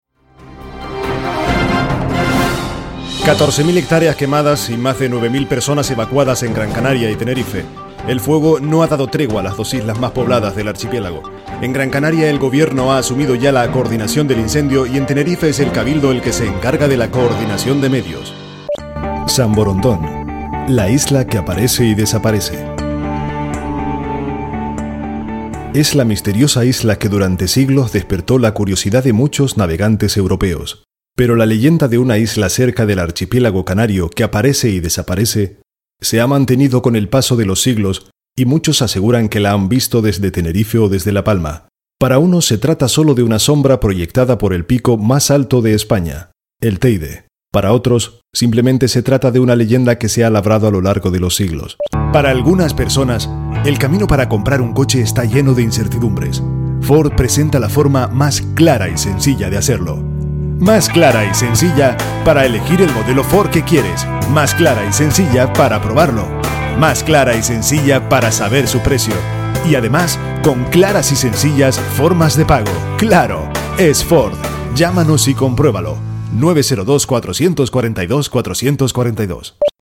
kastilisch
Sprechprobe: Sonstiges (Muttersprache):
I have my own recording studio, with RODE mics and Apple Computers.